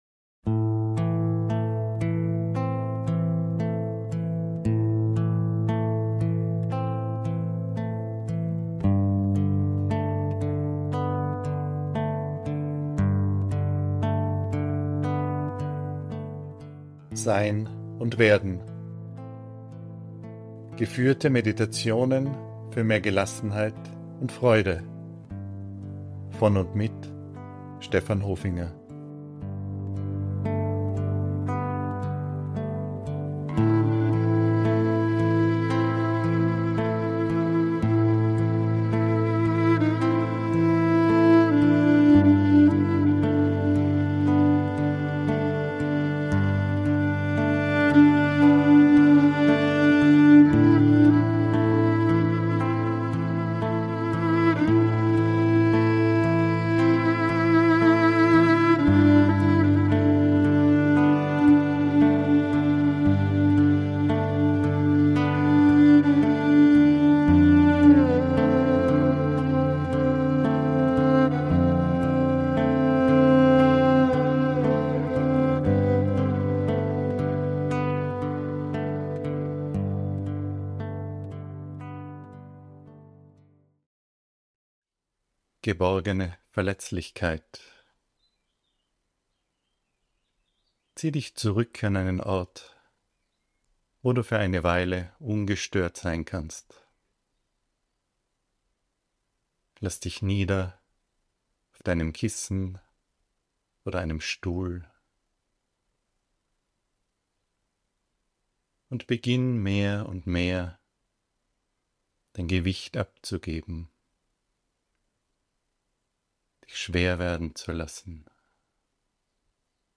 Die Meditation schafft einen bergenden, aber zugleich weiten Raum,...